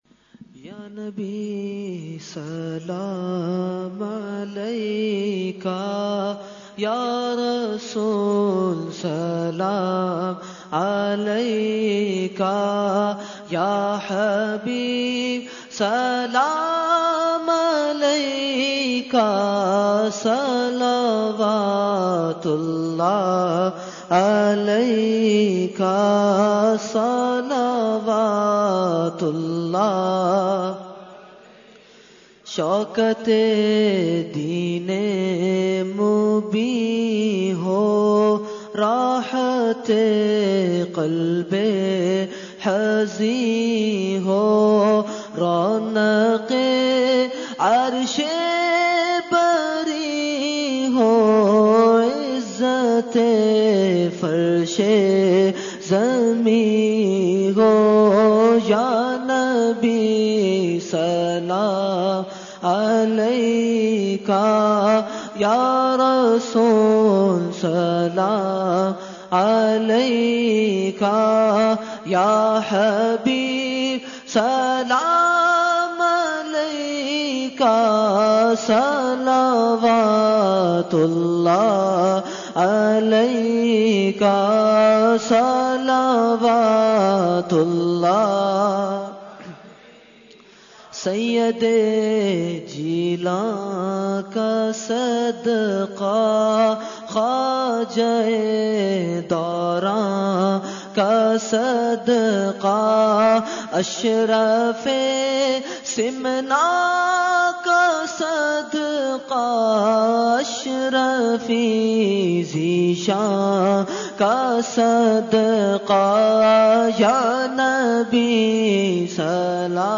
Category : Salam | Language : UrduEvent : 11veen Shareef 2018-2